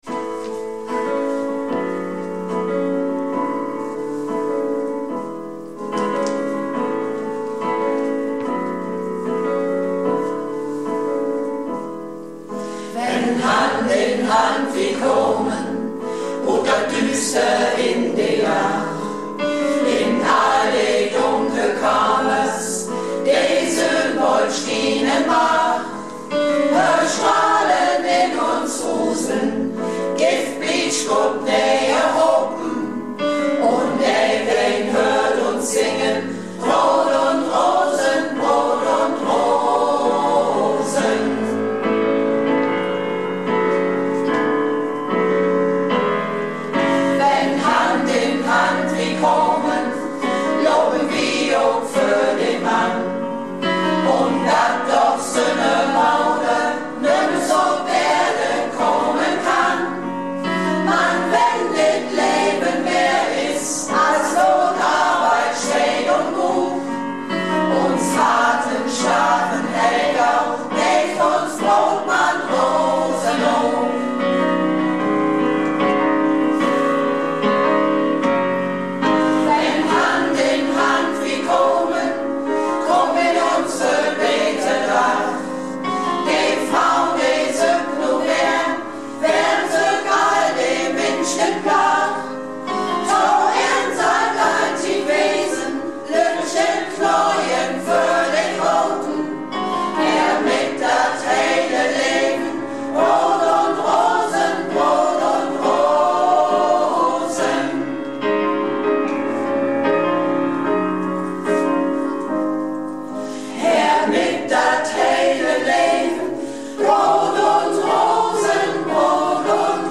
Malle Diven - Probe am 22.08.17